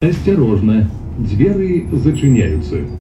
уведомление , голосовые